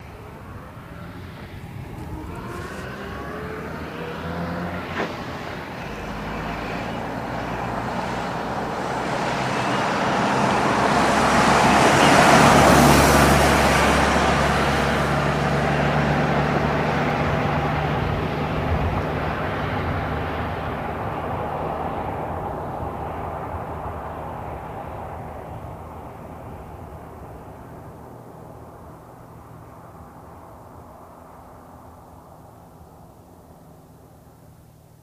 Chevy Tudor 1932 | Sneak On The Lot
Chevy Tudor 1932 6 Cylinder, Medium Slow By